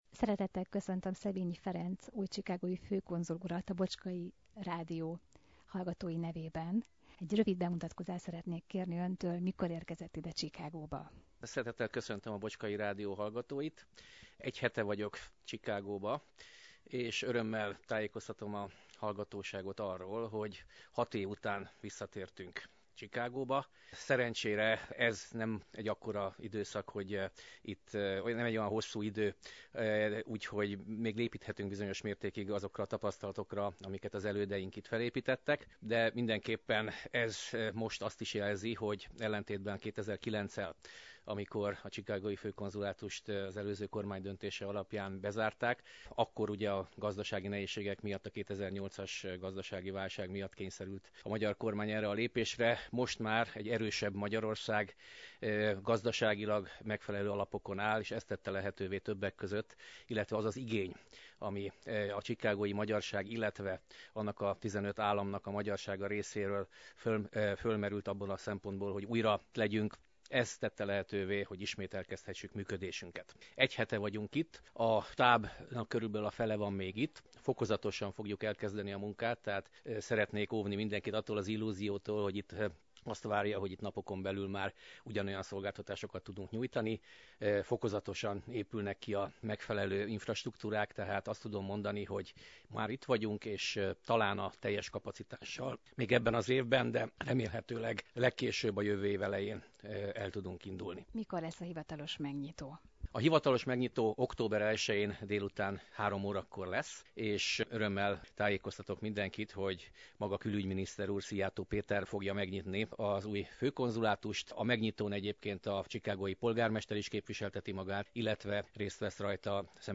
A chicagói főkonzul látogatása a Taste of Hungary Fesztiválon – Bocskai Rádió
Szebényi Ferenc köszönetét fejezte ki a Taste of Hungary szervezőinek, amely a magyar konyha bemutatásával  terjeszti a magyar kultúrát.